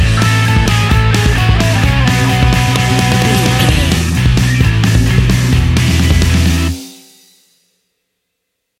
Ionian/Major
energetic
driving
aggressive
electric guitar
bass guitar
drums
hard rock
distortion
instrumentals
distorted guitars
hammond organ